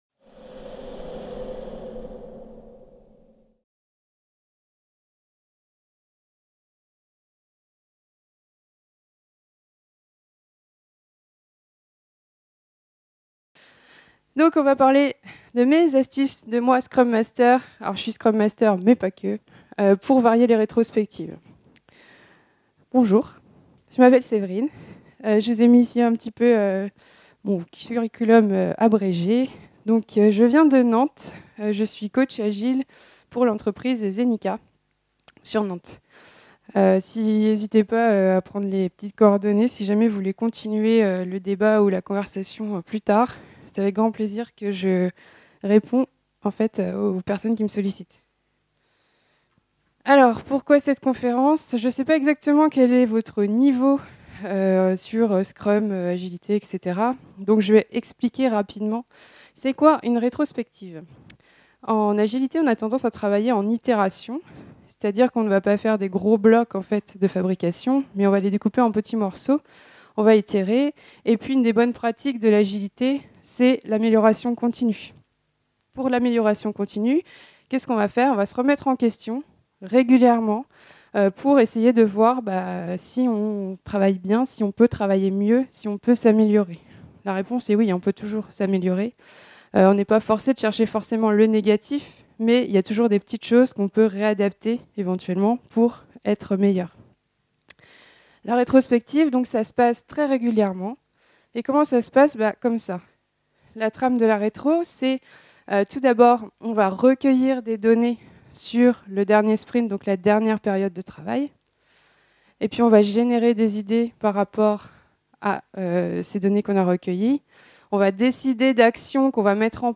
Type : Conférence